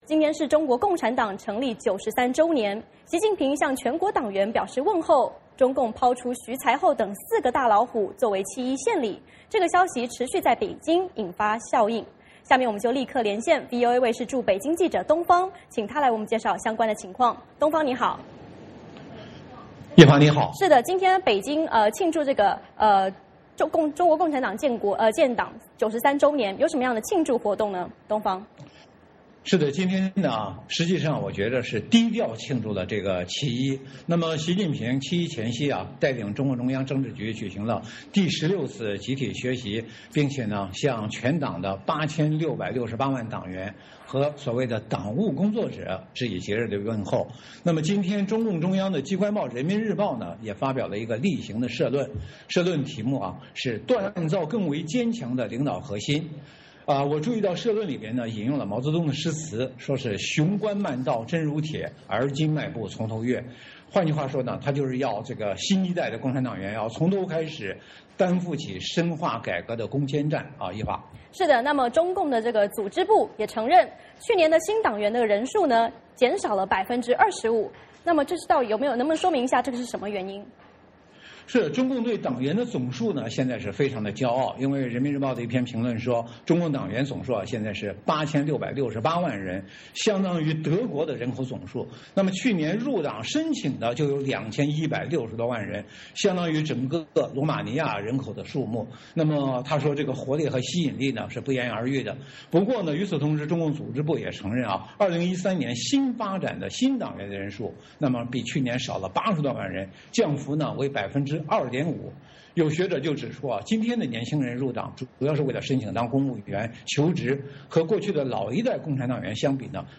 VOA连线：中国共产党成立93周年 徐才厚等老虎落马为七一献礼